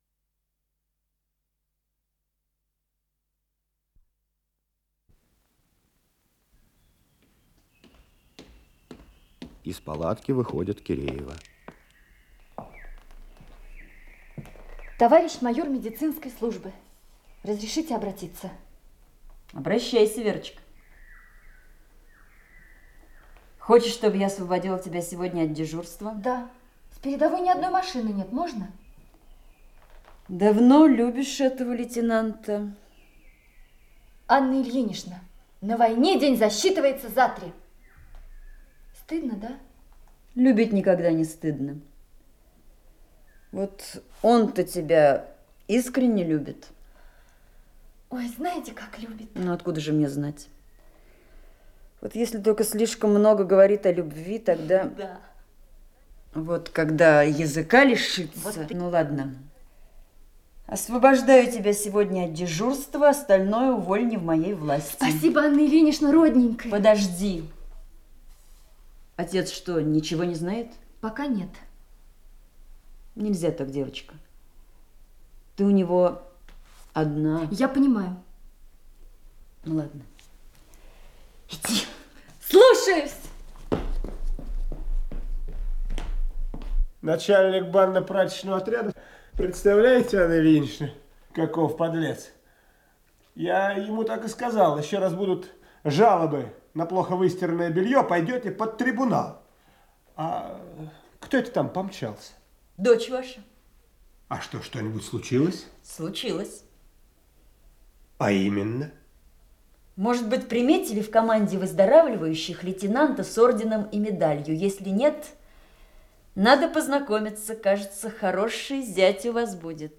Исполнитель: Артисты центрального академического театра советской армии
Спектакль центрального академического театра советской армии по пьесе Ивана Стаднюка